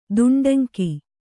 ♪ duṇḍaŋki